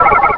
pokeemerald / sound / direct_sound_samples / cries / whismur.aif